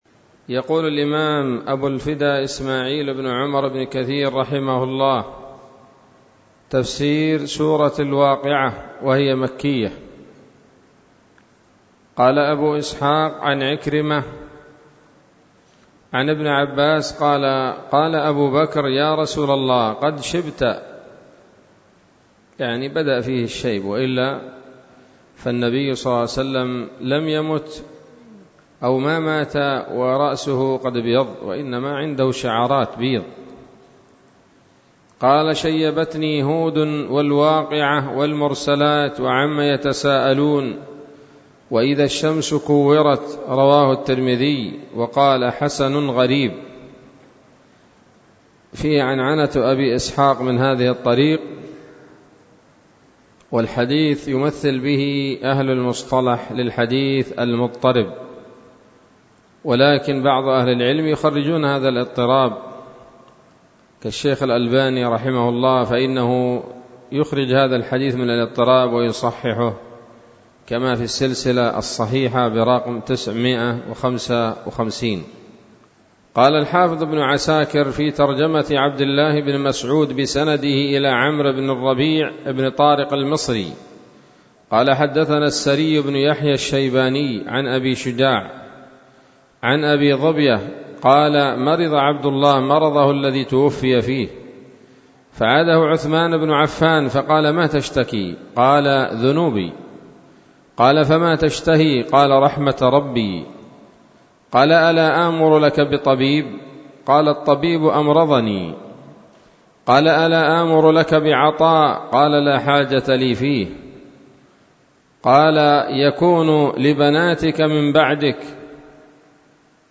الدرس الأول من سورة الواقعة من تفسير ابن كثير رحمه الله تعالى